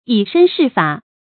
注音：ㄧˇ ㄕㄣ ㄕㄧˋ ㄈㄚˇ
以身試法的讀法